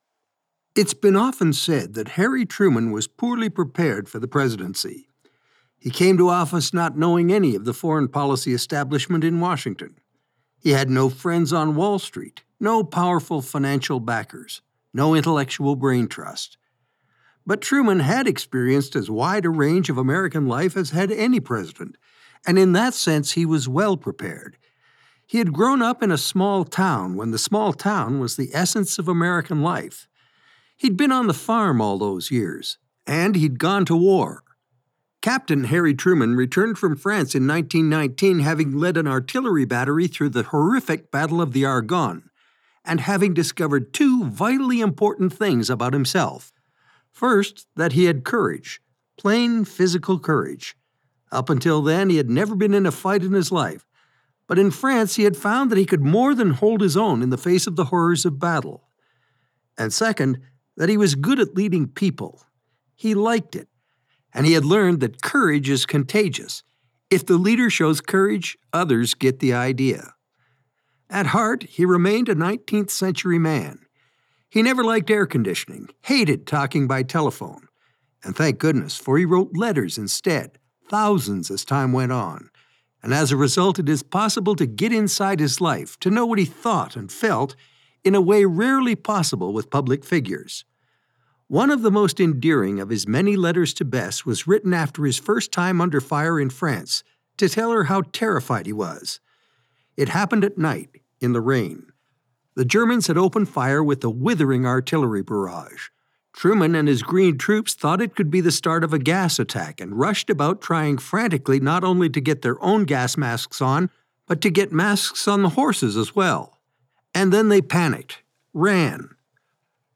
A signature voice - mature, warm, and engaging
TRUMAN - audiobook - biography
Middle Aged